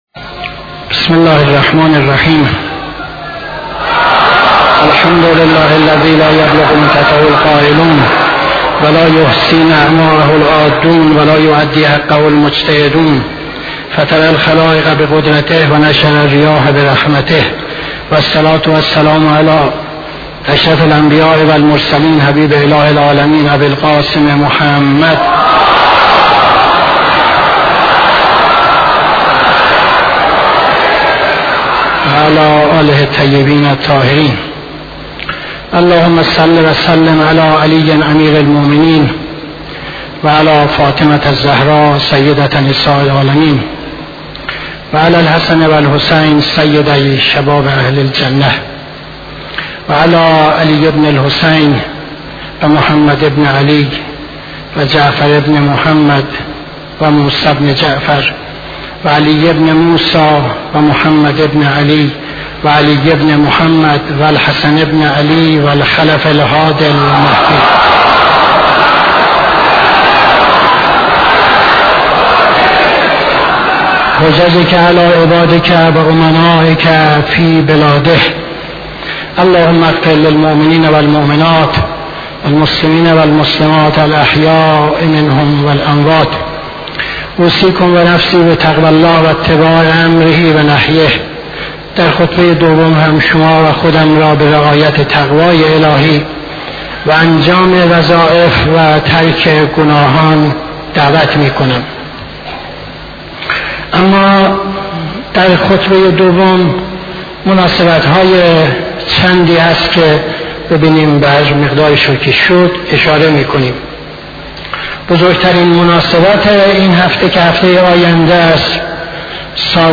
خطبه دوم نماز جمعه 27-08-73